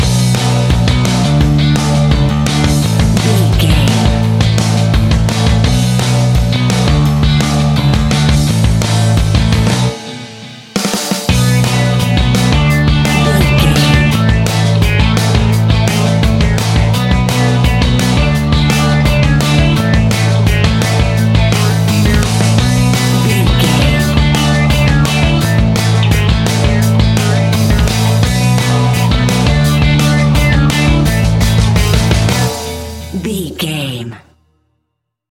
Mixolydian
fun
energetic
uplifting
instrumentals
upbeat
uptempo
groovy
guitars
bass
drums
piano
organ